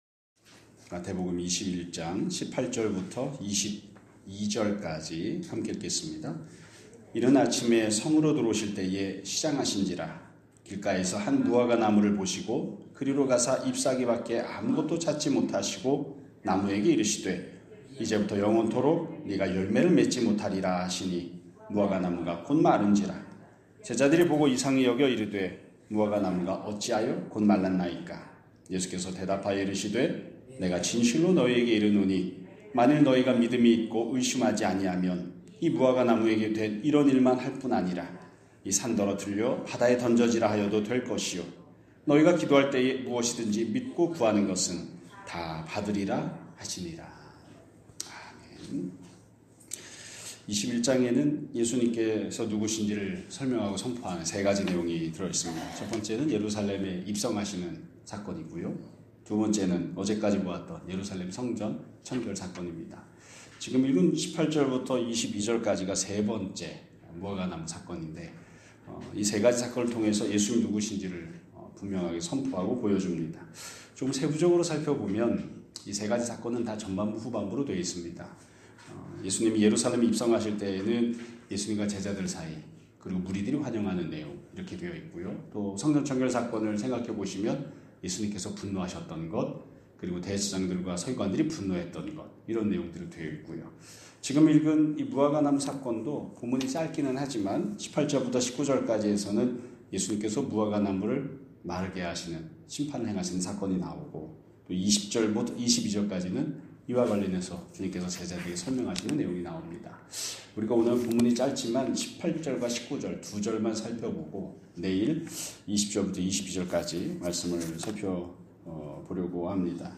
2026년 1월 27일 (화요일) <아침예배> 설교입니다.